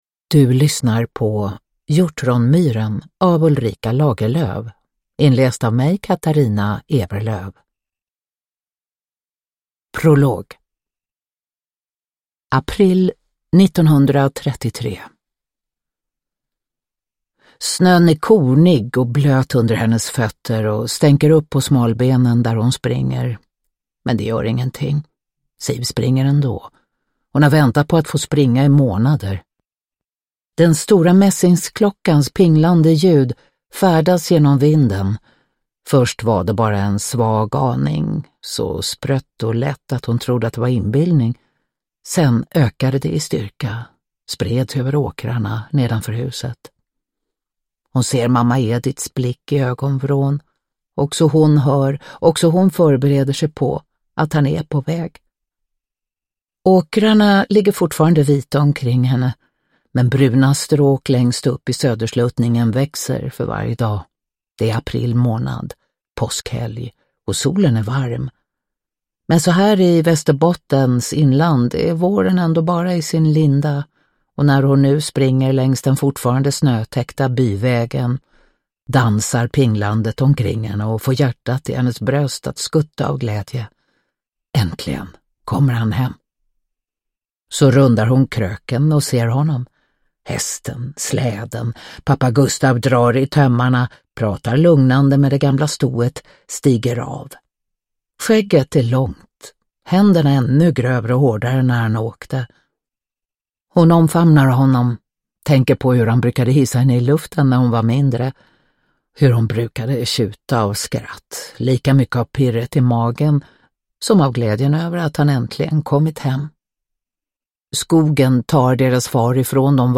Hjortronmyren – Ljudbok
Uppläsare: Katarina Ewerlöf